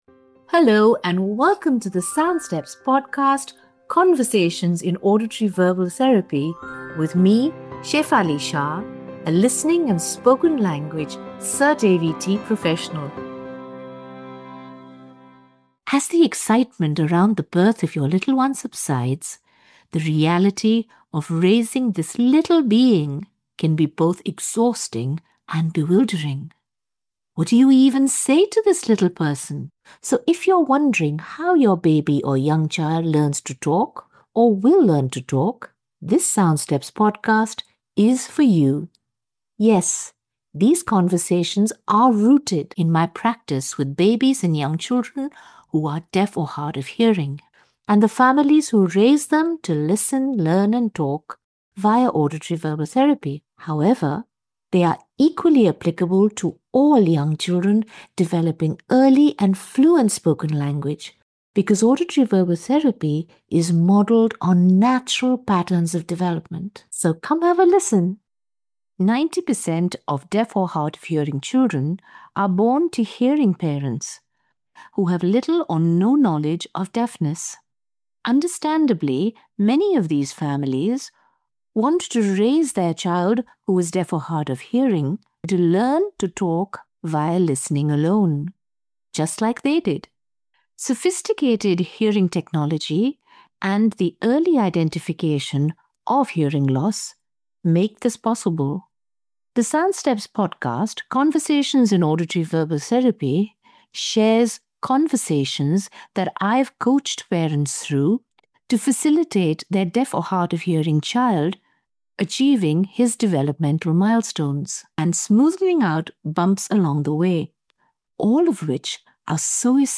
a spirited workshop discussion with three couples